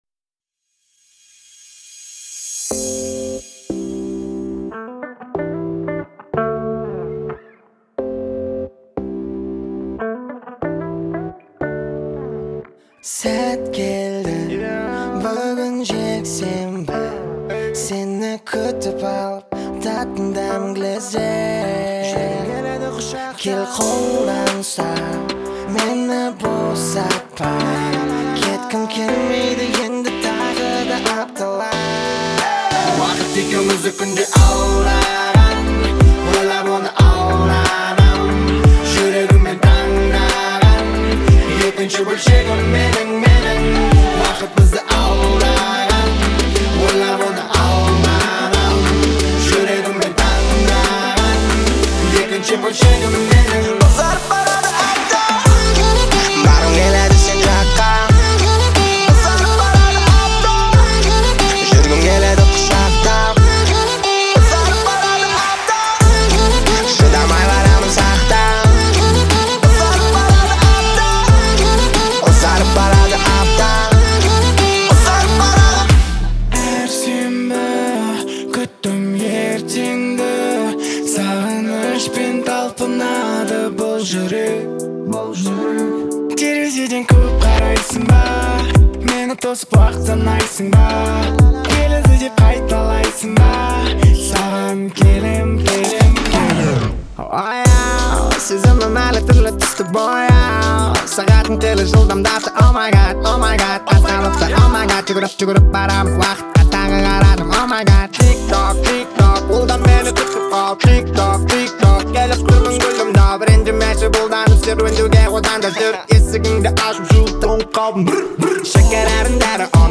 мягкий вокал